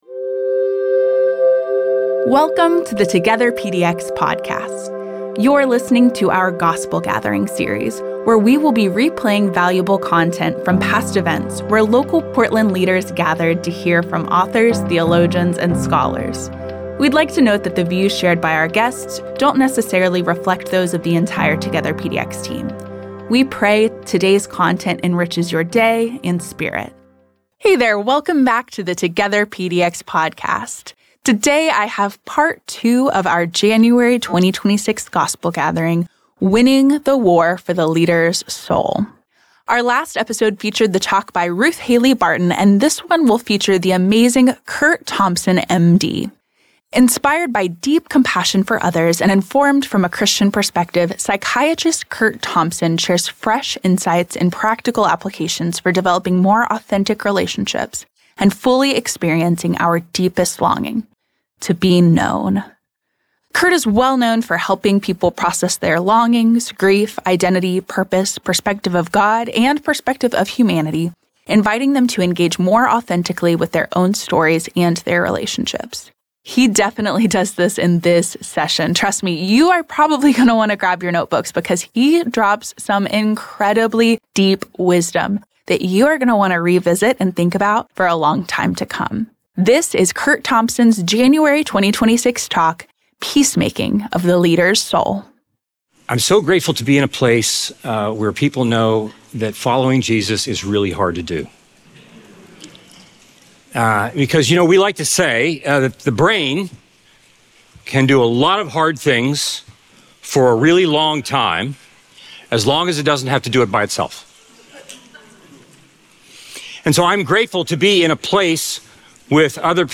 shares in person with leaders from around the Portland Metro Area